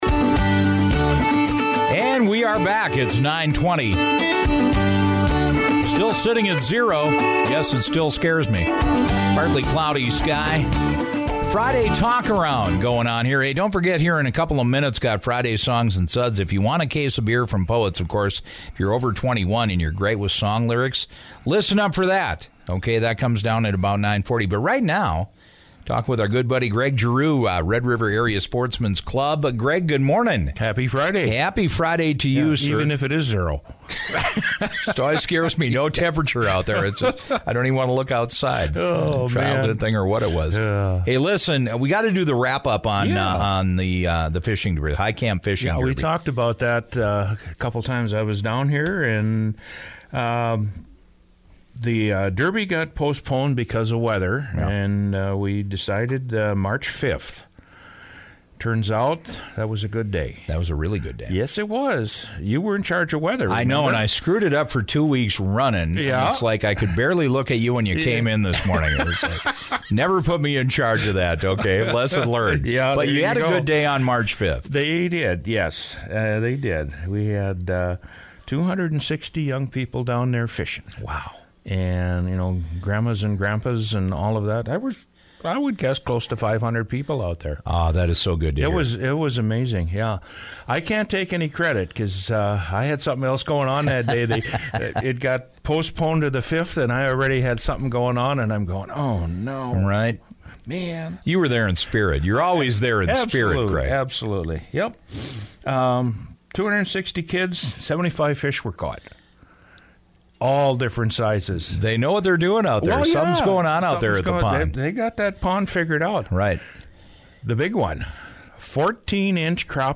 The Neil Heitkamp Fishing Derby hosted over 260 kids on Heitkamp Pond on Saturday, March 5th. Red River Area Sportsmen’s member spoke with KBMW Morning Show’s